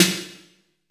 AMBIENT S8-R 2.wav